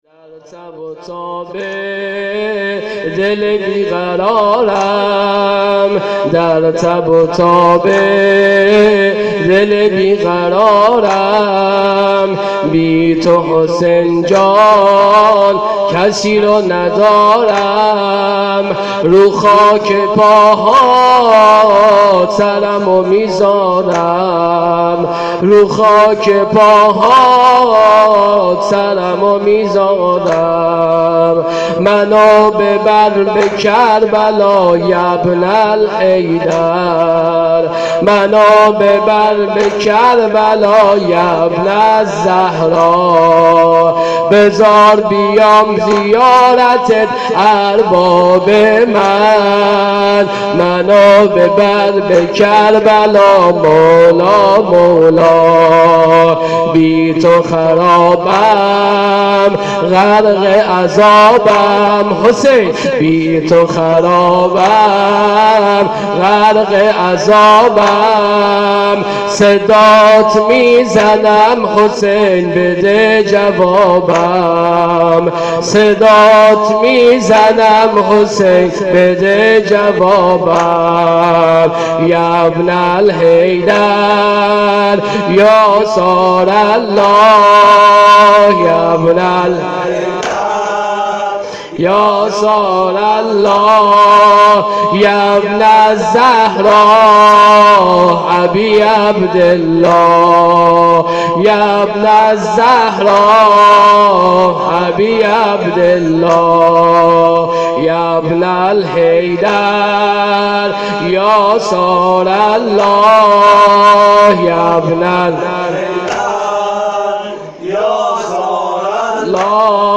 شهادت حضرت رقیه96